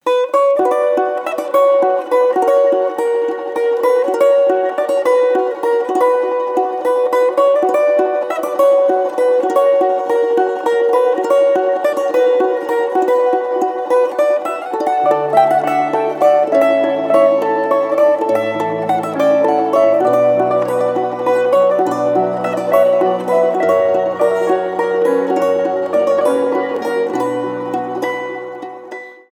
балалайка
поп
романтические , без слов , инди